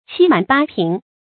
七滿八平 注音： ㄑㄧ ㄇㄢˇ ㄅㄚ ㄆㄧㄥˊ 讀音讀法： 意思解釋： 形容沒有空馀的地方。